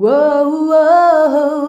UOUOUOH 2.wav